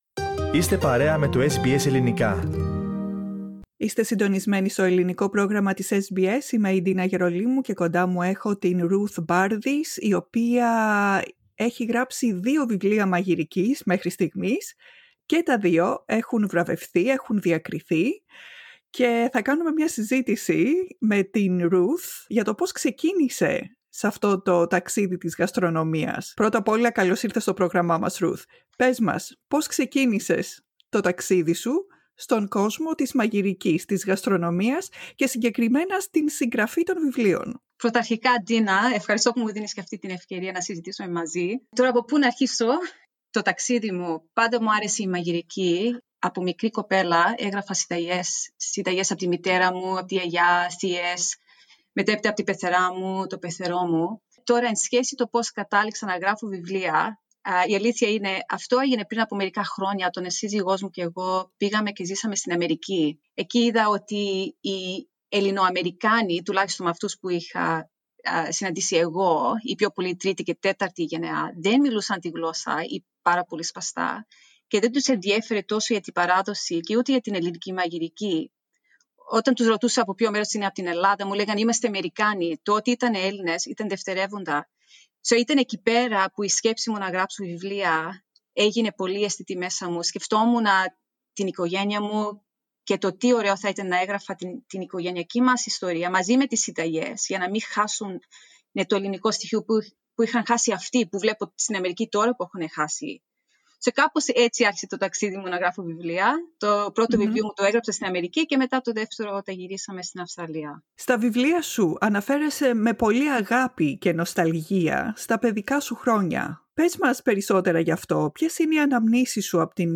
Ακούστε τη συνέντευξη για περισσότερες λεπτομέρειες και για να μάθετε τα μυστικά του στιφάδου!